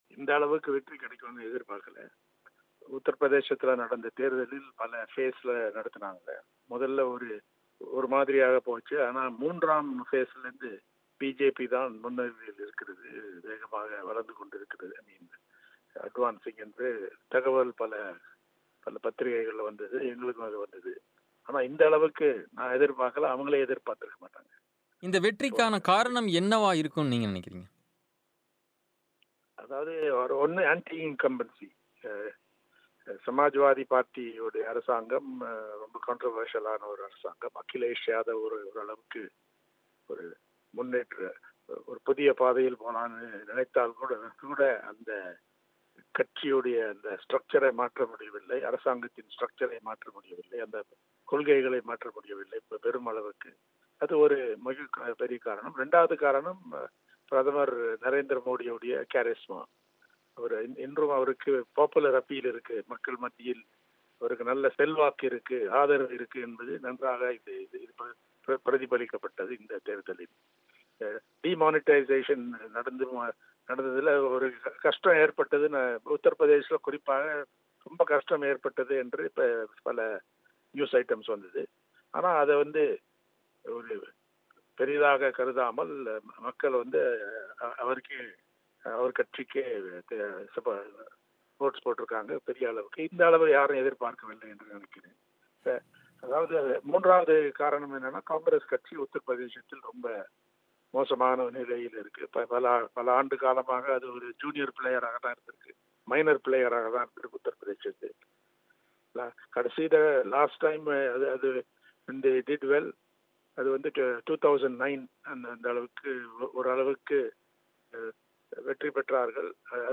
இதுகுறித்து, பிபிசி தமிழுக்கு பேட்டியளித்த ராம், மோடிக்கான ஆதரவு தொடர்வதற்கான காரணங்களைப் பட்டியலிட்டார்.